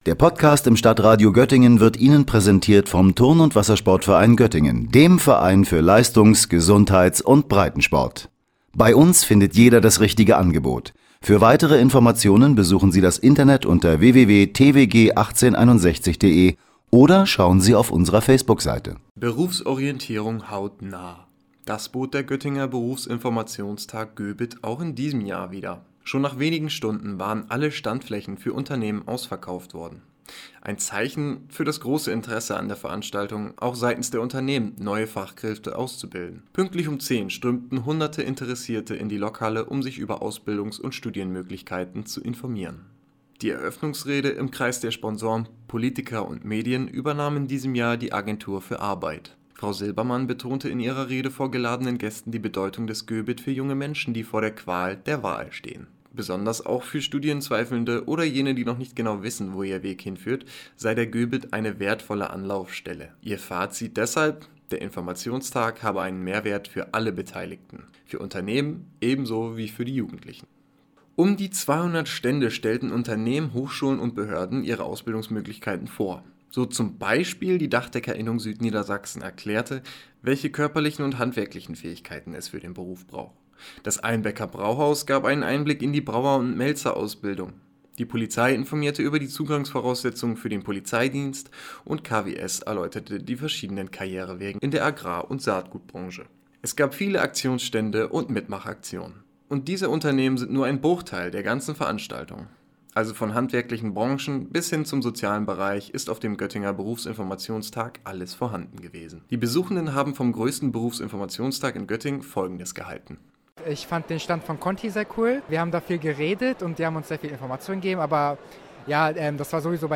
Hunderte junge Menschen strömten in die Lokhalle, um sich über ihre berufliche Zukunft zu informieren.